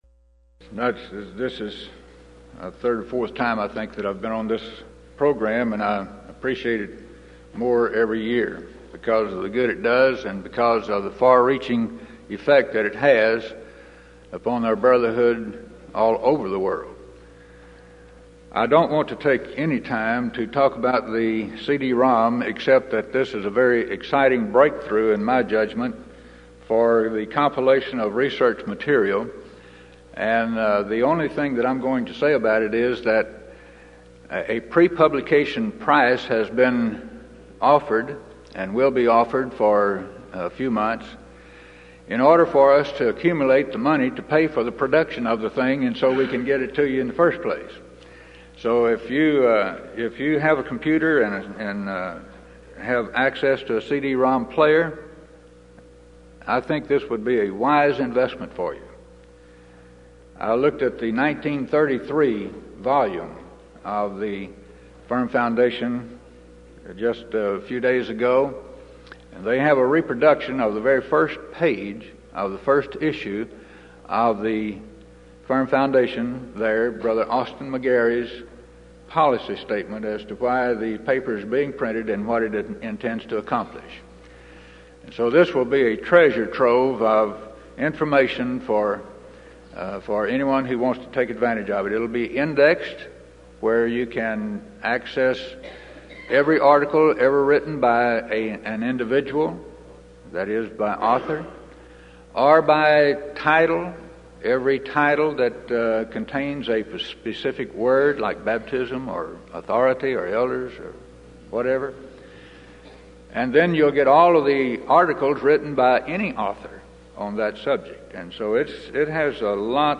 Series: Denton Lectures Event: 1994 Denton Lectures